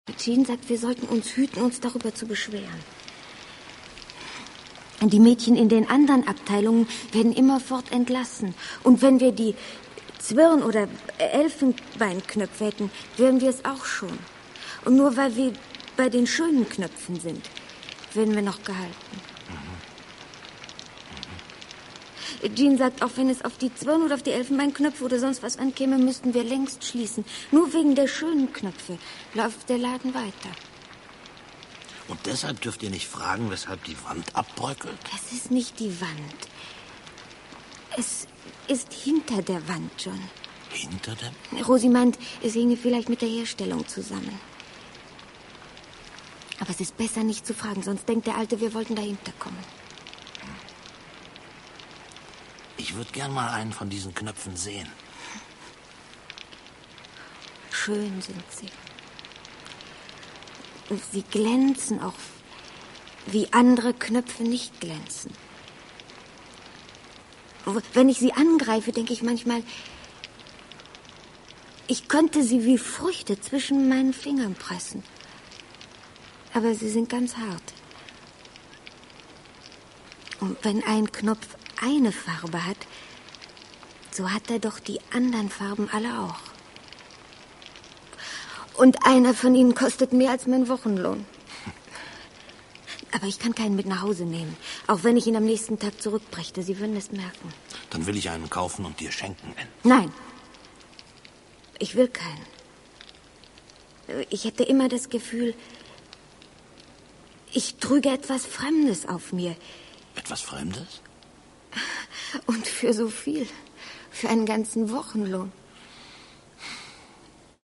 Hörspiel, Lesungen und Originalaufnahmen
Ilse Aichinger, Christiane Hörbiger, Thomas Holtzmann (Sprecher)
Absurd, expressiv und zerbrechlich wirken Ilse Aichingers Hörspiel, ihre Gedichte und Prosa aus den Jahren 1953 bis 1987, u. a. ihre berühmte "Spiegelgeschichte". So zurückhaltend intonieren Christiane Hörbiger, Thomas Holtzmann und die Autorin selbst das Unheimliche, dass man fast nicht merkt, wie einem der Boden unter den Füßen weggezogen wird.